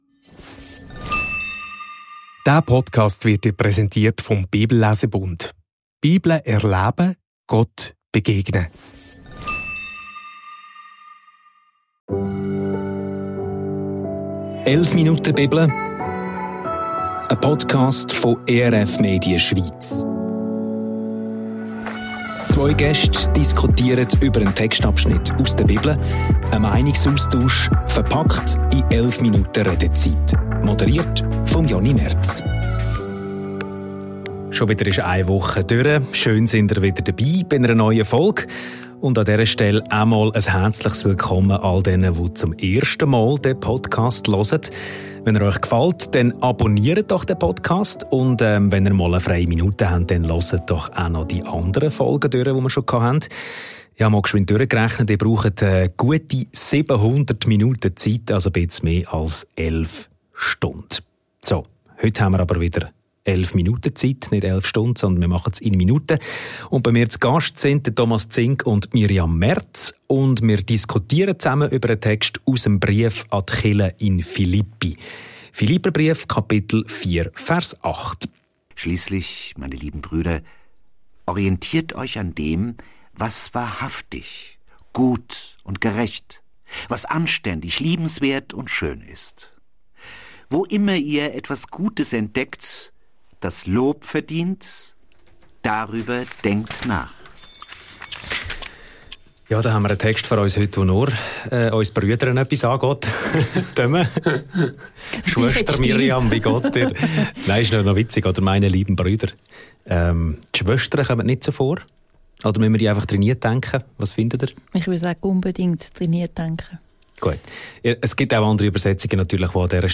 Was mit einem kleinen Exkurs beginnt, endet am Schluss bei einer eher philosophischen Debatte über eine ganz neue Wertekultur. Und zusätzlich werben die drei dafür, dass man einen Welttag einführt, an dem man bei seinen Mitmenschen das Gute entdecken sollte.